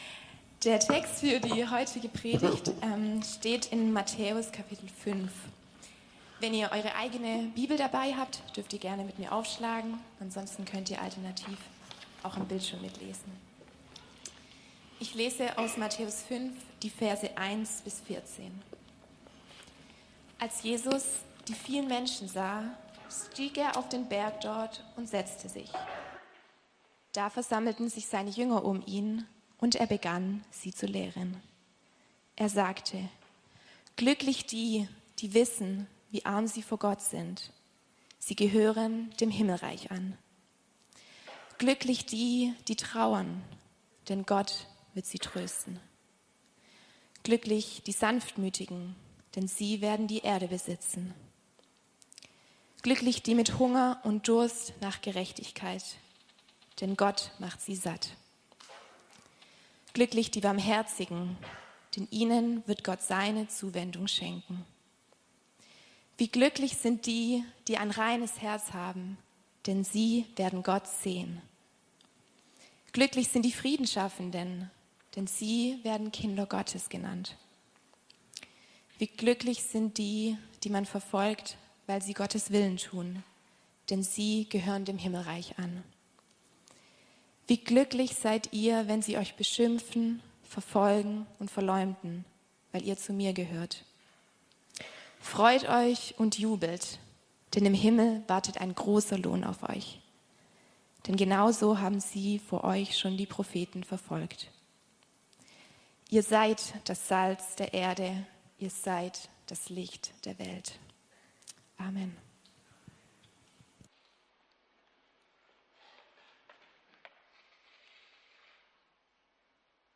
Predigten – Er-lebt.